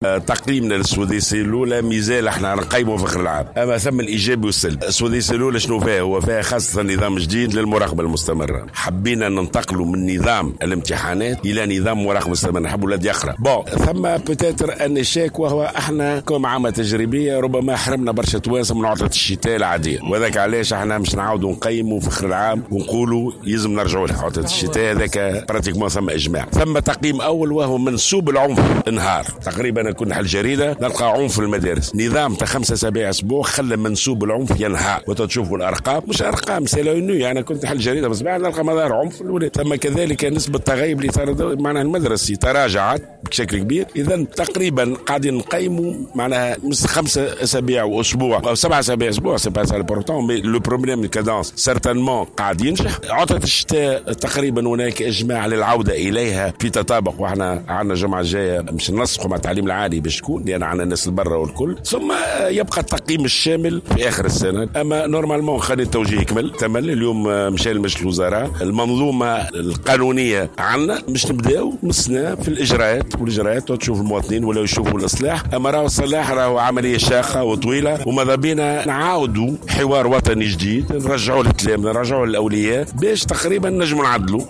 وأعلن الوزير في تصريح صحفي على هامش تنظيم النهائيات الوطنية المدرسية للعدو الريفي بالمنستير، ان الوزارة تعتزم تنظيم حوار وطني جديد مع التلاميذ والأولياء للتمكن من القيام بالتعديل.